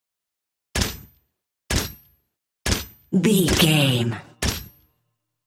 Pistol Firing with Silencer 01 | VGAME
Filled with 5 sounds(44/16 wav.) of Pistol Firing(Five single shots) with silencer.
Sound Effects
Adobe Audition, Zoom h4
silencer
handgun